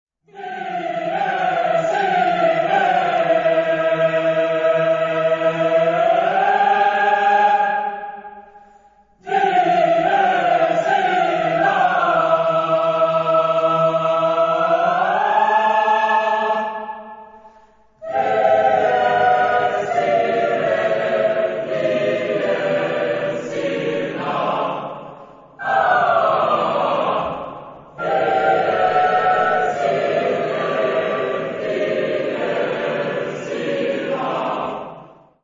Genre-Style-Forme : contemporain ; Sacré
Type de choeur : 4S-4A-4T-4B  (16 voix mixtes )
Tonalité : atonal